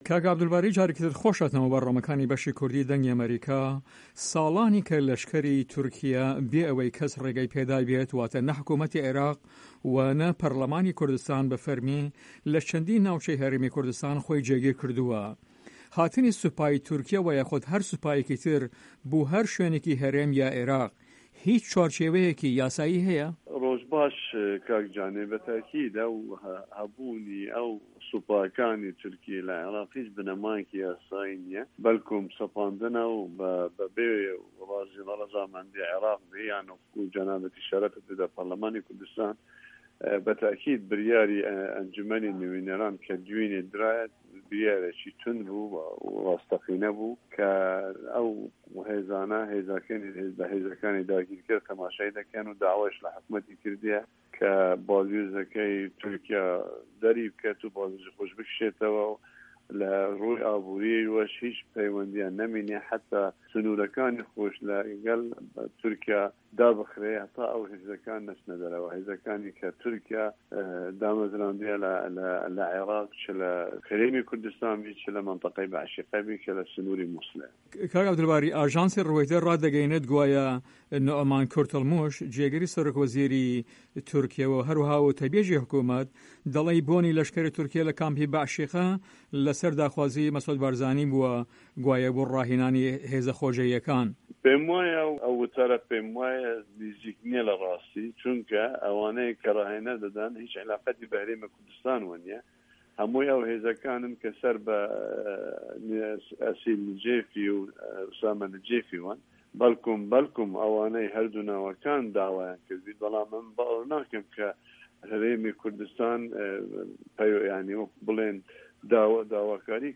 Interview with Abdul Bari Zibari